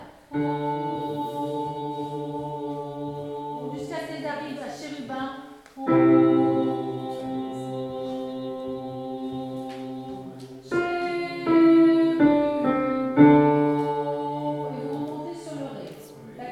hommes-mp3 31 janvier 2021